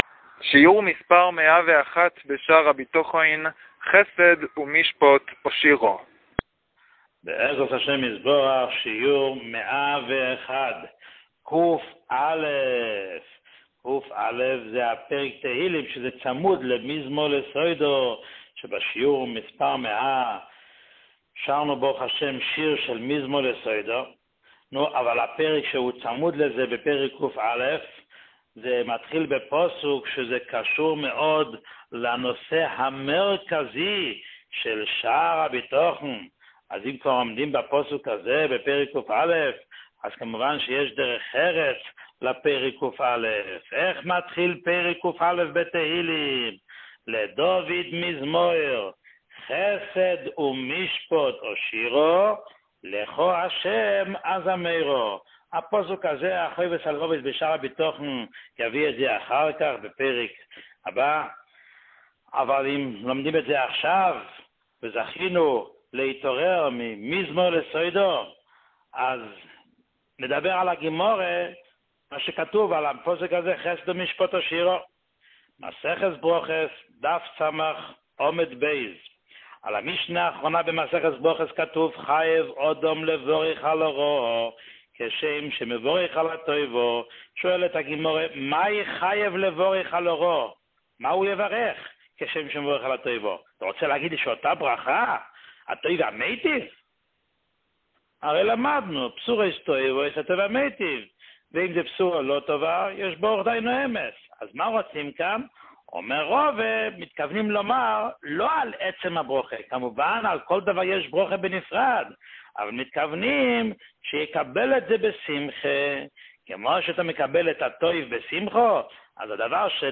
שיעור 101